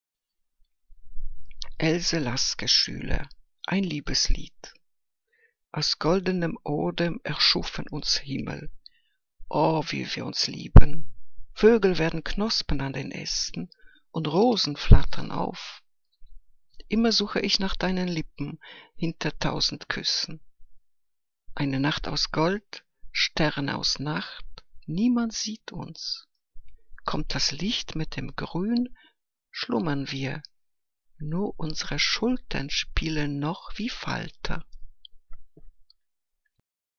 Liebeslyrik deutscher Dichter und Dichterinnen - gesprochen (Else Lasker-Schüler)